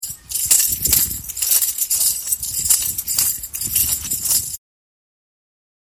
これがフットタンバリンだ。
ｼﾞｬﾝｼﾞｬﾝｼﾞｬｼﾞｬｯｼﾞｬﾝｼﾞｬｼﾞｬｯｼﾞｬﾝｼﾞｬｼﾞｬｯｼﾞｬﾝ